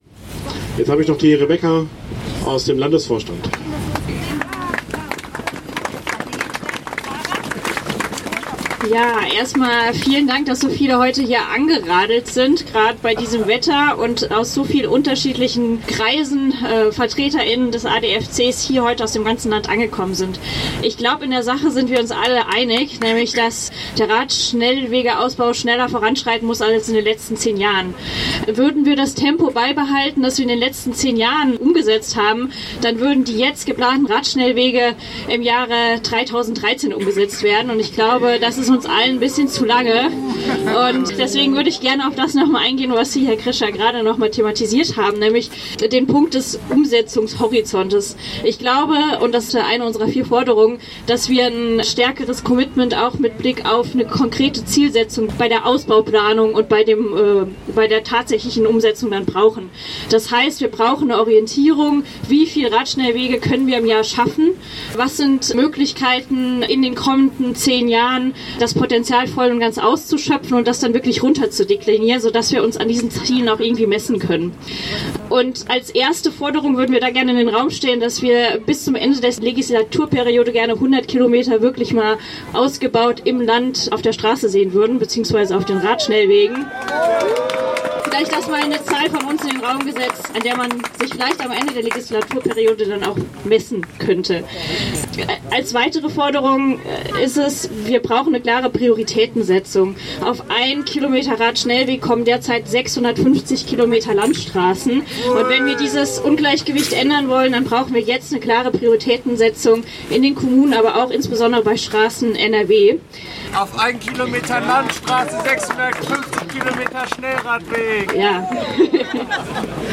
Kapitel 4: Abschlusskundgebung